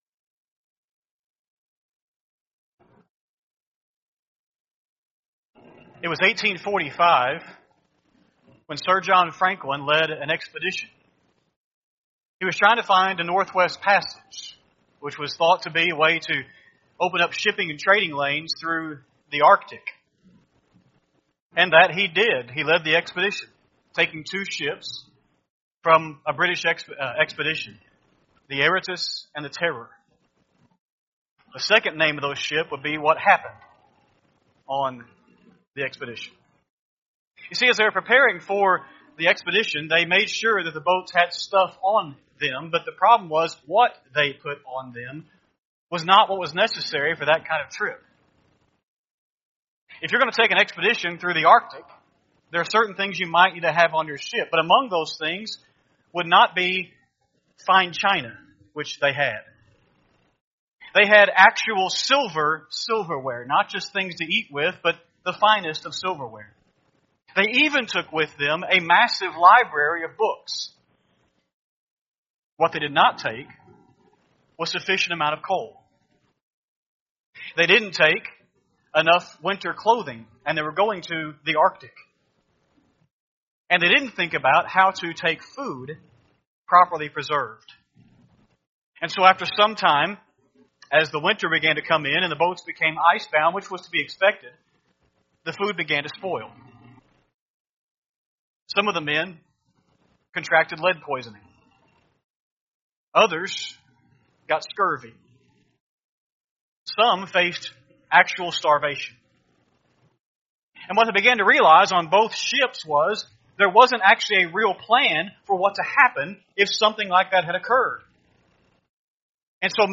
4-6-25-Sunday-AM-Sermon.mp3